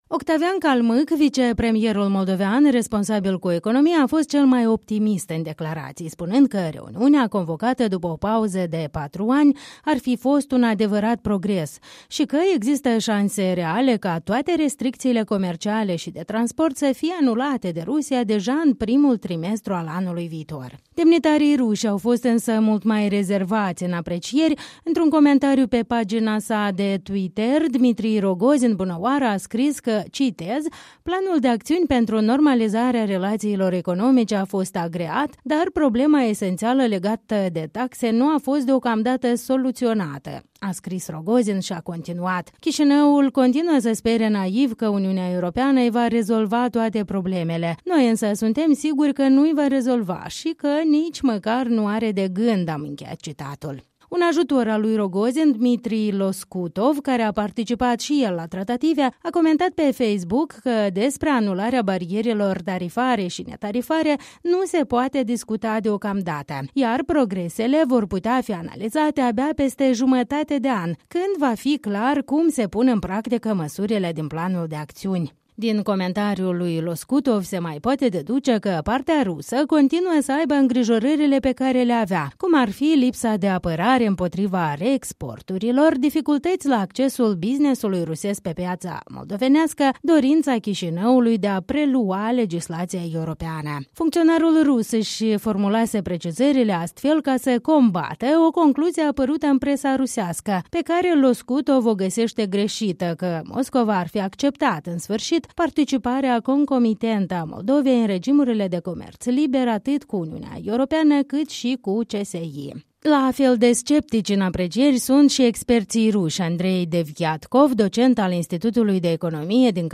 O relatare